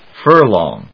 /fˈɚːlɔːŋ(米国英語), fˈəːlɔŋ(英国英語)/